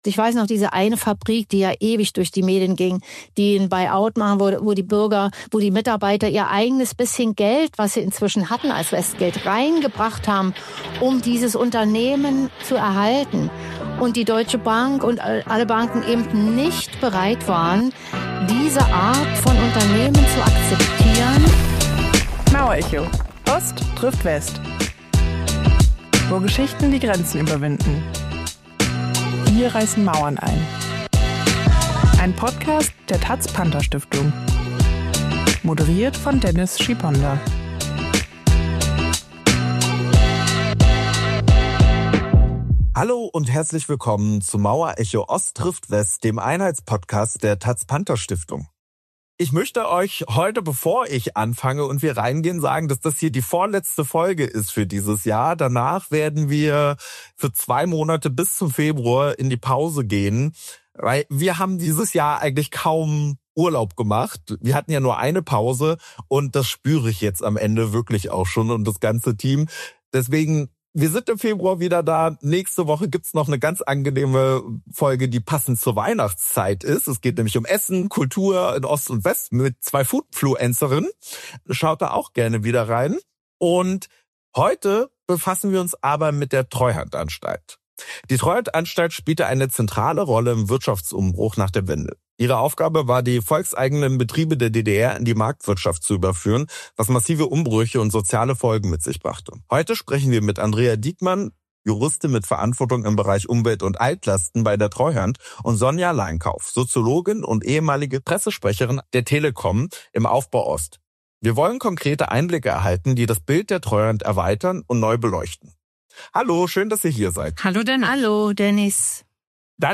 Im Gespräch berichten sie von ihren persönlichen Erfahrungen während der Transformationsjahre.